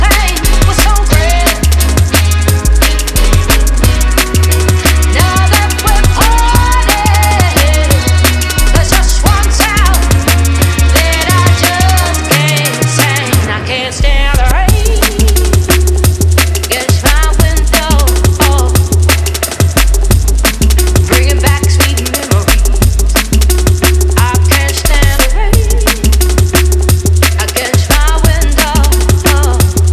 • Dance